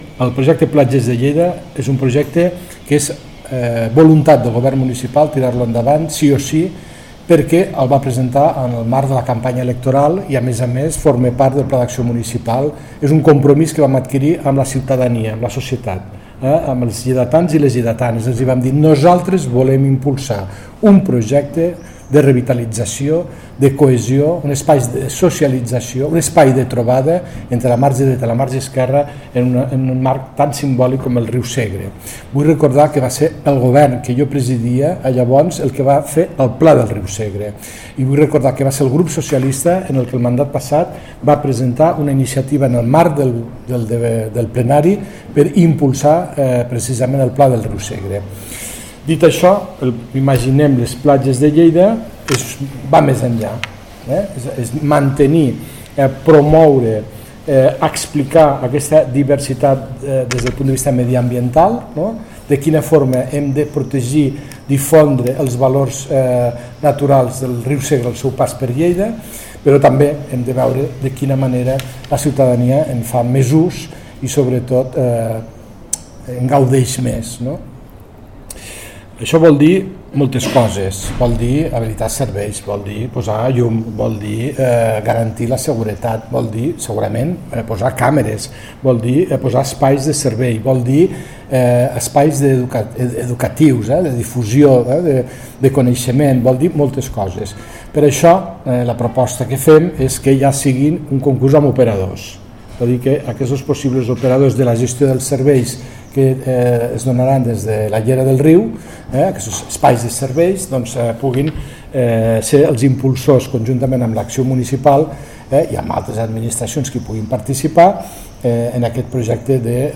Tall de veu del paer en cap, Fèlix Larrosa, sobre els Imaginem Platges Lleida i Antiga estació d'autobusos.